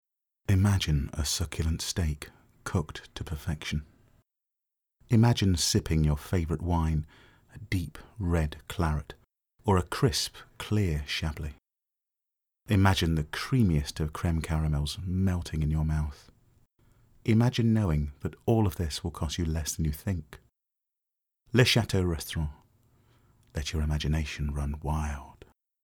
Adverts